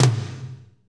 TOM S S M18L.wav